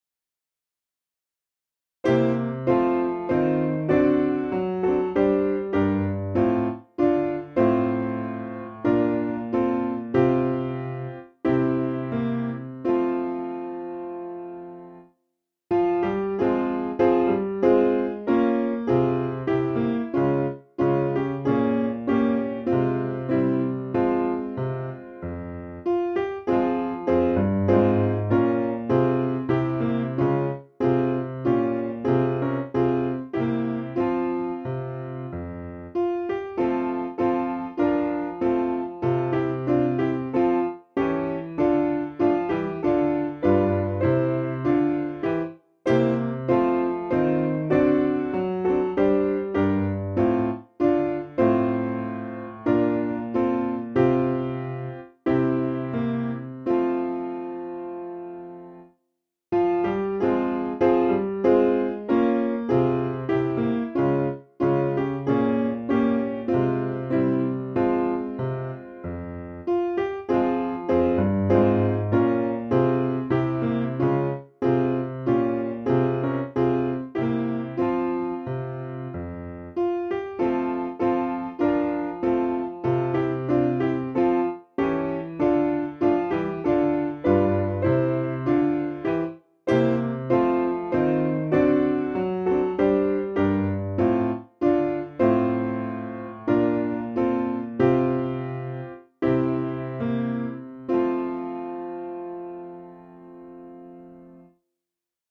Key: f minor